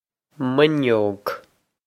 min-yohg
This is an approximate phonetic pronunciation of the phrase.